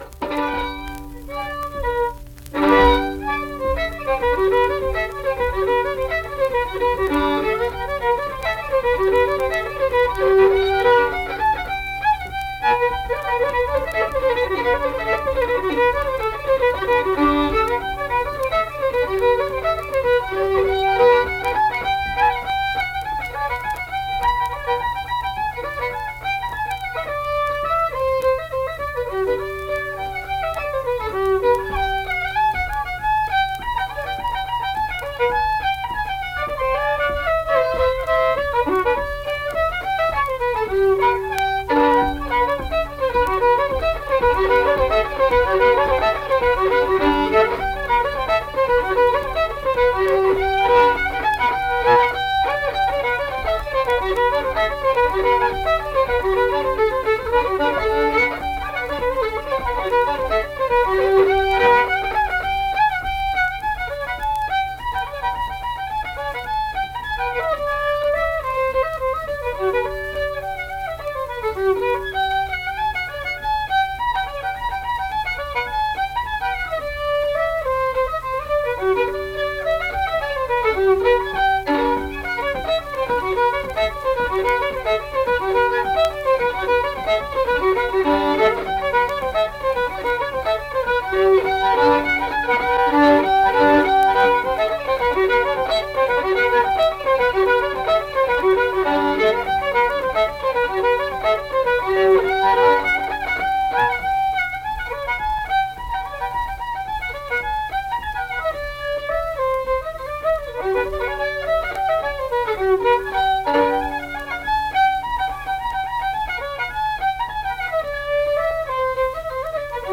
Accompanied guitar and unaccompanied fiddle music performance
Instrumental Music
Fiddle
Pocahontas County (W. Va.), Mill Point (W. Va.)